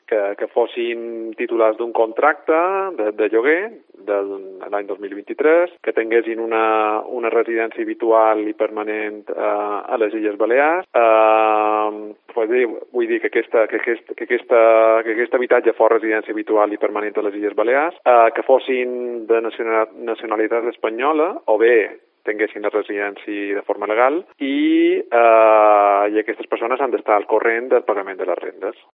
El Director General de Vivienda, José Francisco Reynés, explica en Cope Mallorca todos los detalles